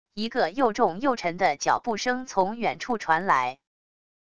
一个又重又沉的脚步声从远处传来wav音频